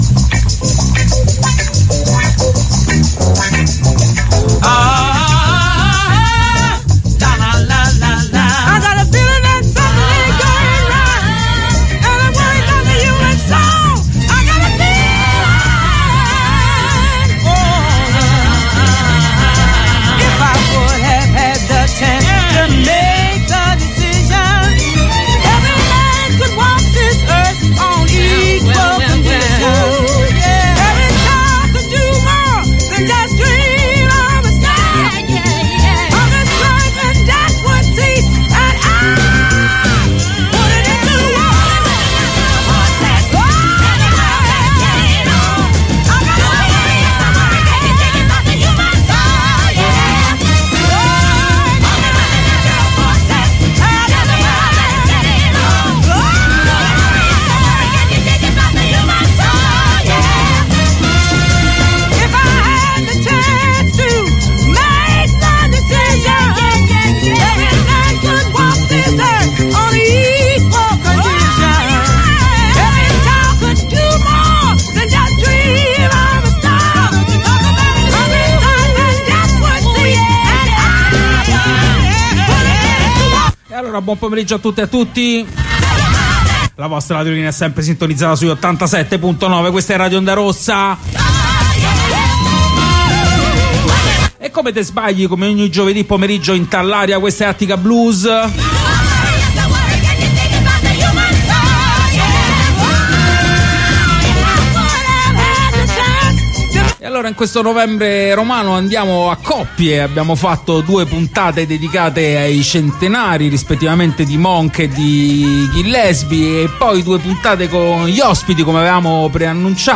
Ospite in studio della puntata di oggi